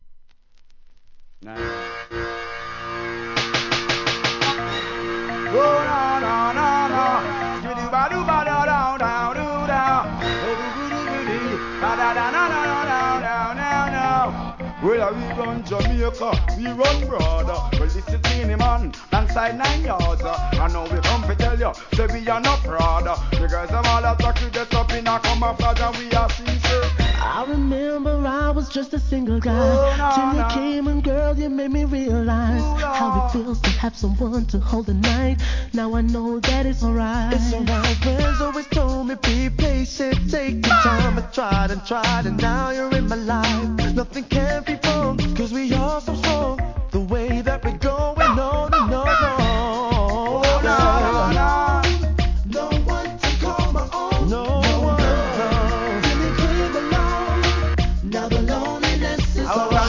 HIP HOP/R&B
硬派UKコーラス・グループによるアコースティックが美しい美メロナンバー！！